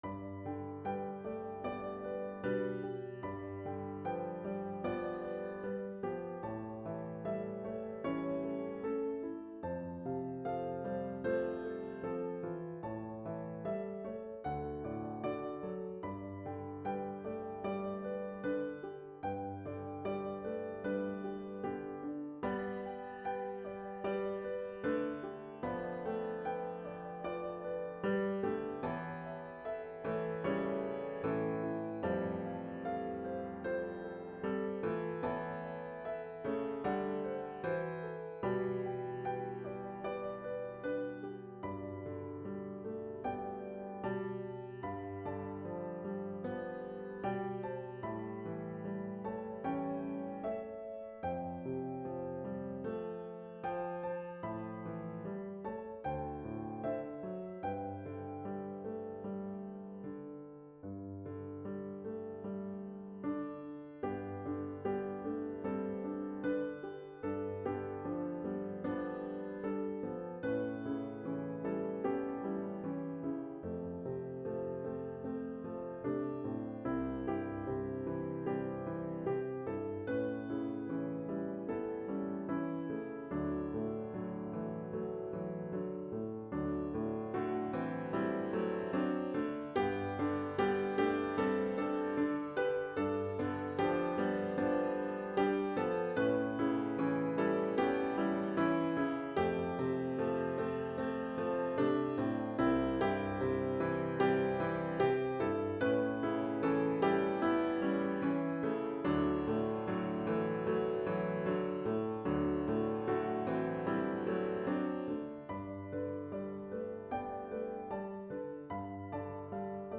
Ballade
The music, comprised of two very simple melodies, is meant to evoke the peace the psalm brings to people. The two melodies undergo subtle variations in accompaniment to a melody that never really changes.